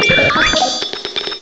cry_not_porygonZ.aif